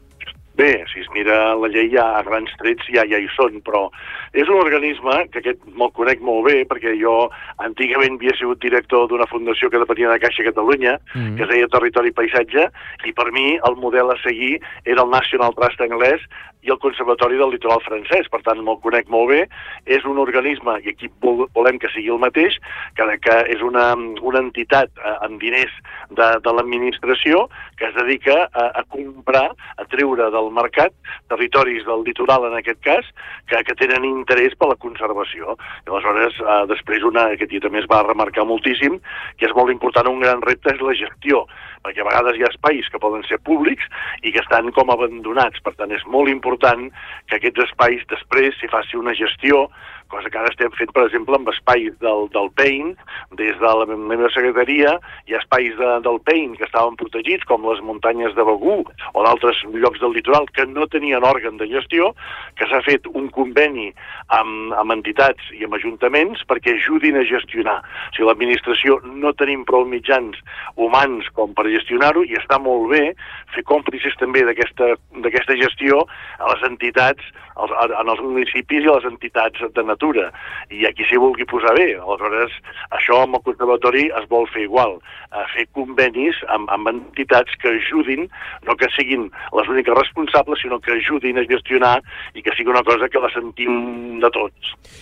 Entrevistes Supermatí
Així ho ha confirmat en una entrevista al Supermatí el secretari de transició ecològica de la Generalitat, Jordi Sargatal, qui assegura que tots els grups parlamentaris estan alineats per aprovar el text en els pròxims mesos.